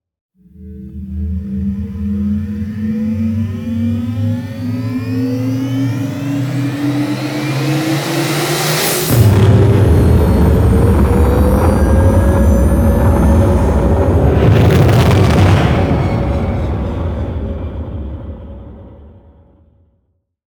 otherlaunch2.wav